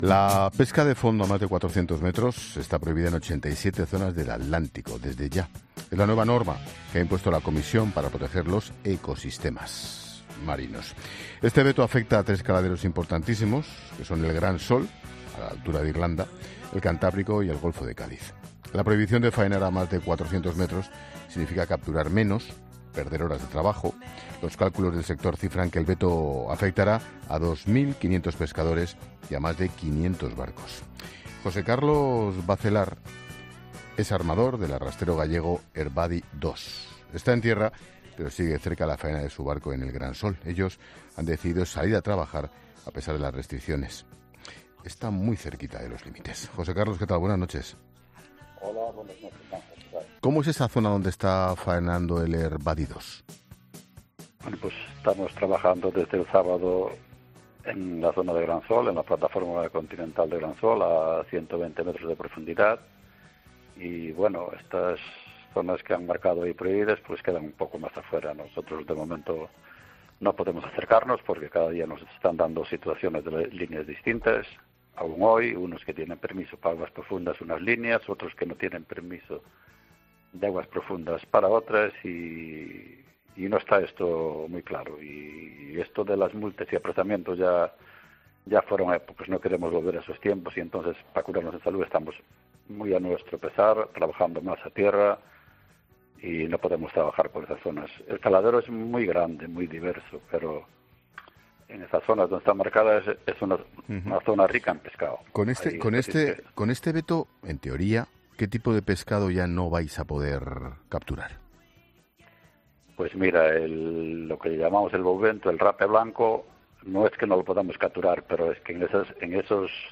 Un armador gallego ha avisado este lunes en La Linterna de las consecuencias que tendrá para la pesca el veto anunciado por la Comisión Europea en ciertos caladeros.